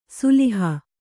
♪ suliha